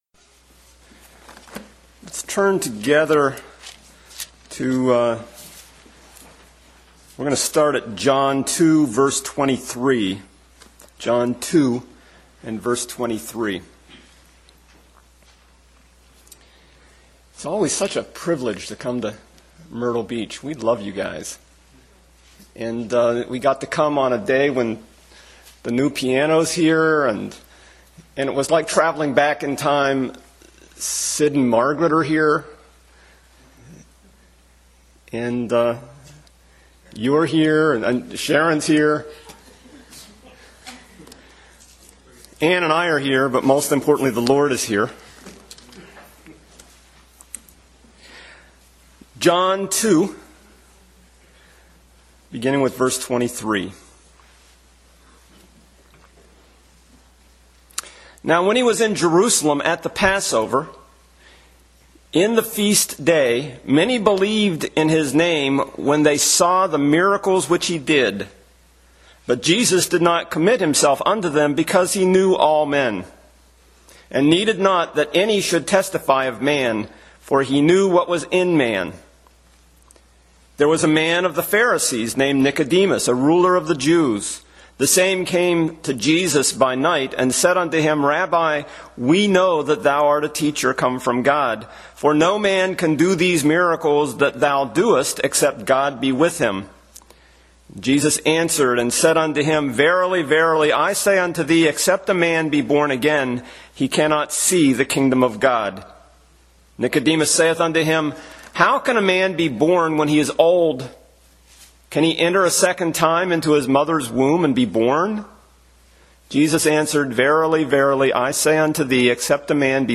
John 3 Service Type: Sunday School Bible Text